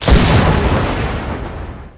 deployables_explosion.wav